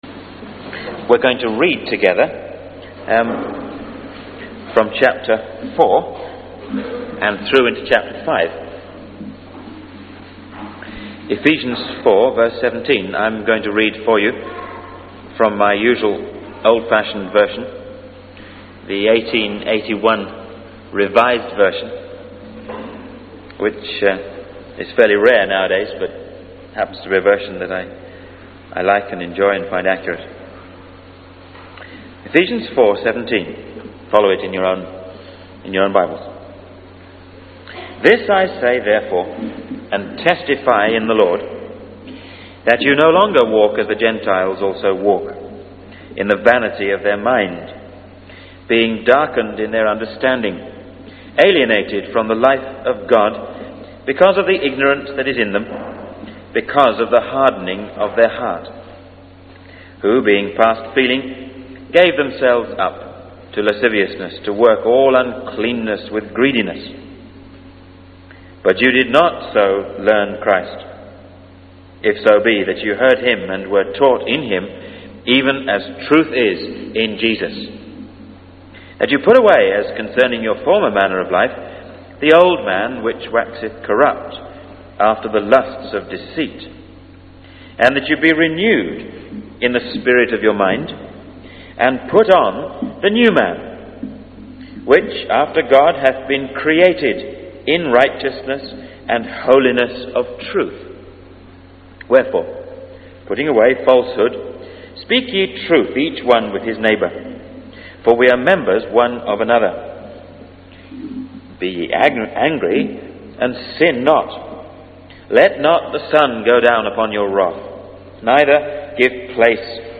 In this sermon, the preacher emphasizes the importance of not allowing Satan to influence our thoughts and attitudes.